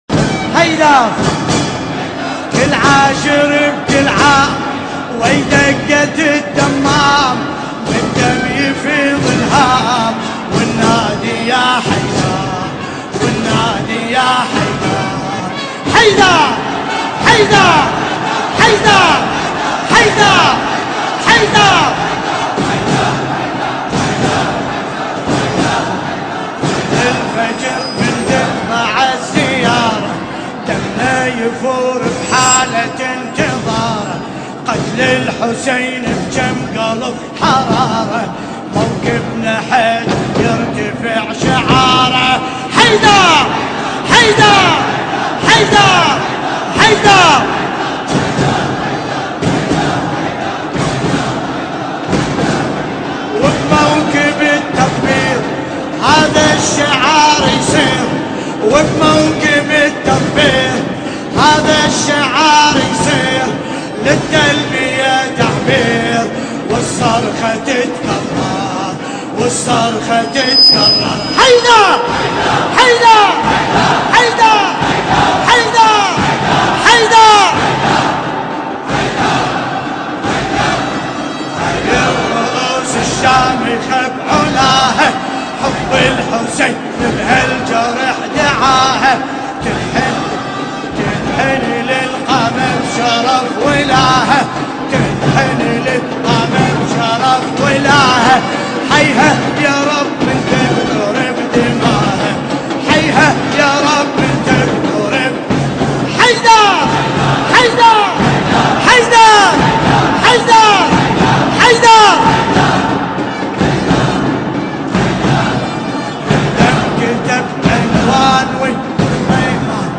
القارئ: باسم الكربلائي التاريخ: ليلة العاشر من شهر محرم الحرام 1434 هـ ، الكويت.